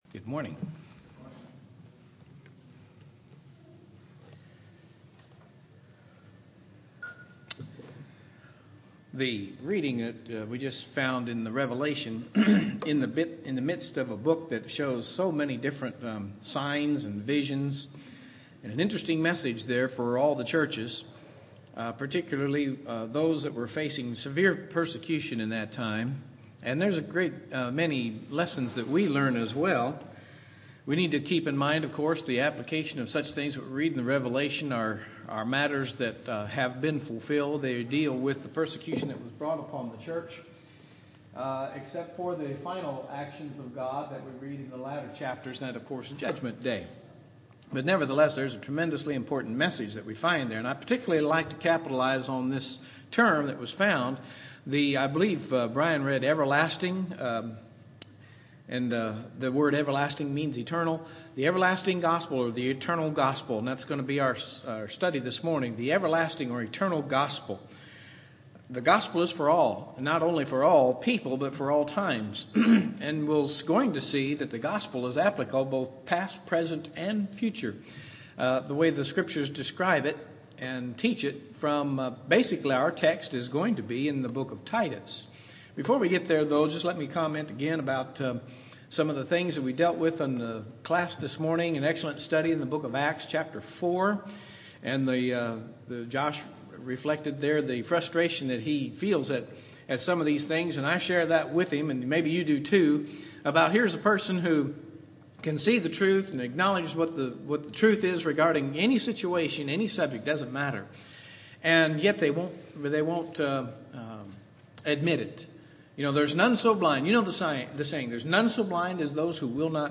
Sermons from the New Testament